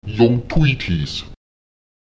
Achten Sie hier auch auf die Verschiebung der Betonung auf die Silbe vor diesem Suffix (siehe Abschnitt 1.2.3):